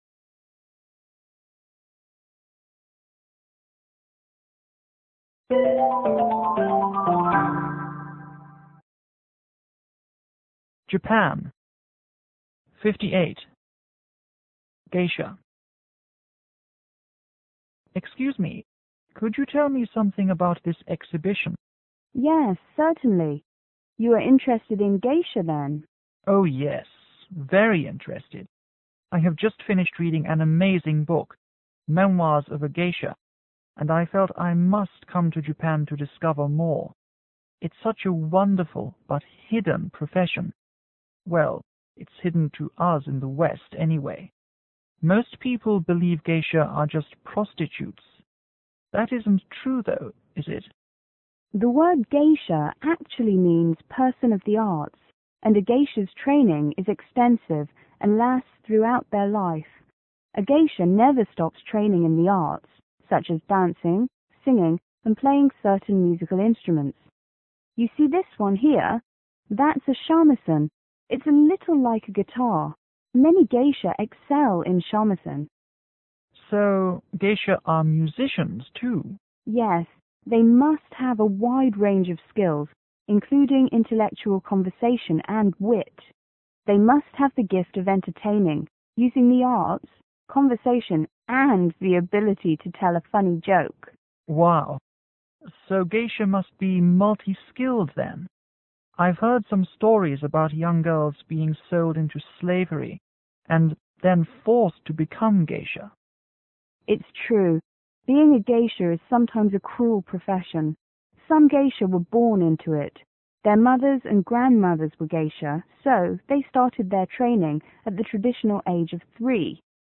T：Tourist    G: Museum guide